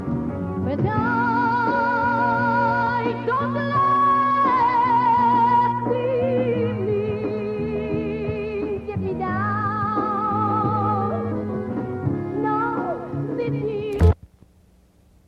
...and oh my lord, it was DEFINATELY recorded in the 1970s!